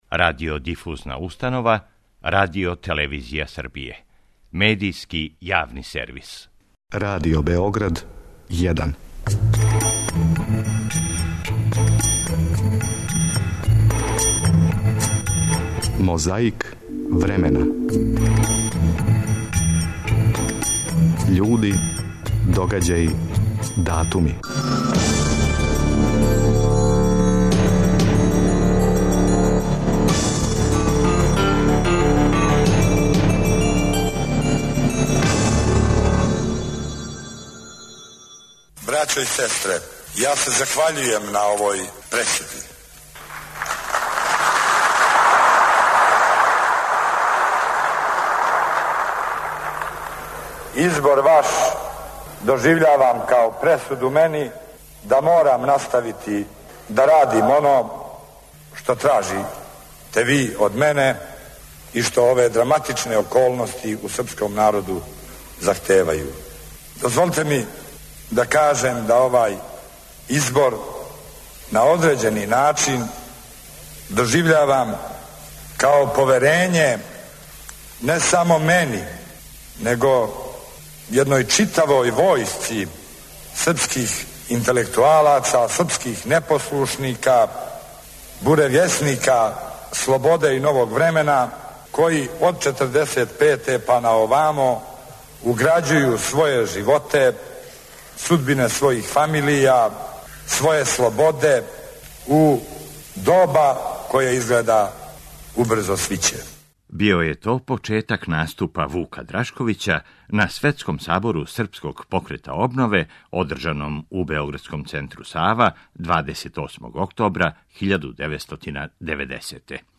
На почетку овонедељне борбе против пилећег памћења - Вук Драшковић на Светском сабору Српског покрета обнове, одржаном у београдском центру Сава, 28. октобра 1990.
Чућете шта је тим поводом, тадашњи председник Скупштине Србије, друг Бранислав Иконић, у свом излагању истакао. 26. октобра 2013. године сахрањена је Јованка Броз. Ево шта су том приликом, за емисију „Говори да бих те видео" нашег Другог програма, рекли грађани који су дошли да јој одају пошту.